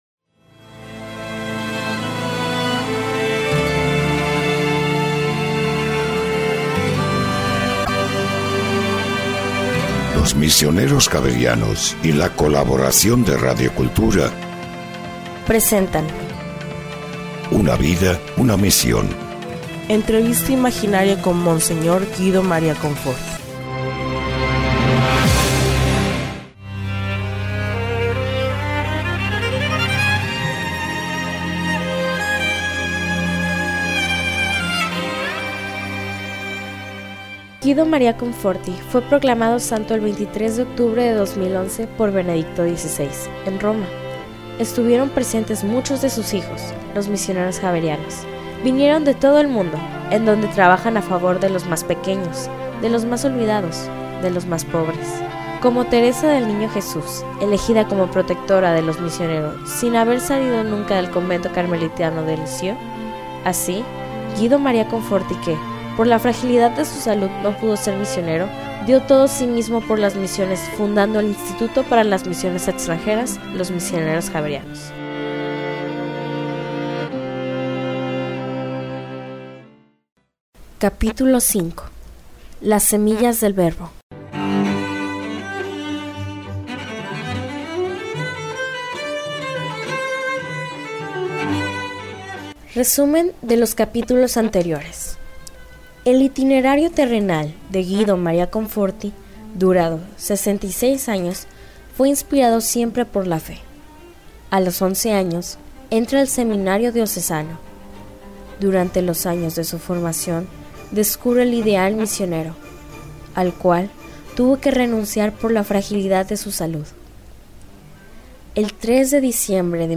Una vida, una misión Entrevista imaginaria con Mons. Guido María Conforti